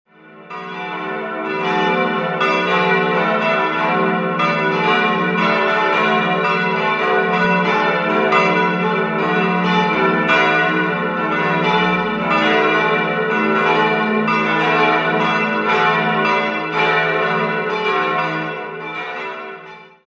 4-stimmiges Gloria-TeDeum-Geläute: e'-fis'-a'-h' Die Marienglocke fis' dürfte noch aus dem 15. Jahrhundert stammen, die drei anderen Glocken (St. Martin, St. Sebastian, St. Florian) wurden 1949 von der Gießerei Petit&Edelbrock in Gescher gegossen.